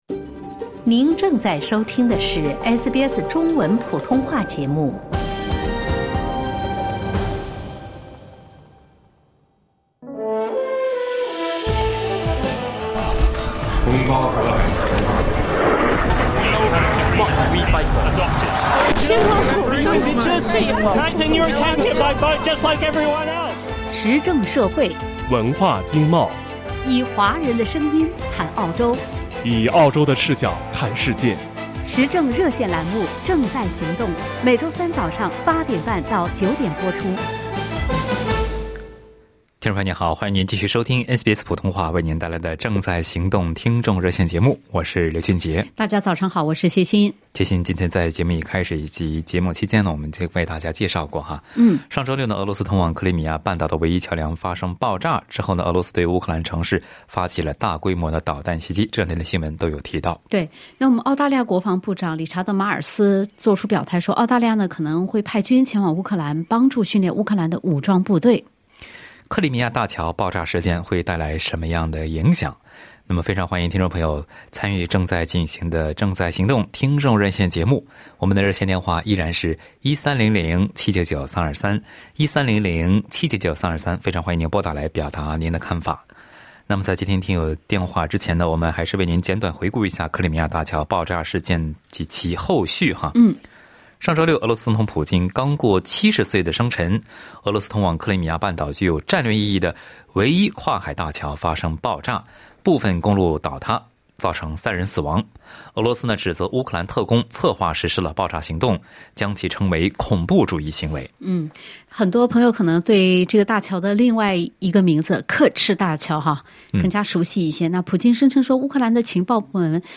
在本期《正在行动》听众热线节目中，听友们就刻赤大桥爆炸事件的影响发表了看法。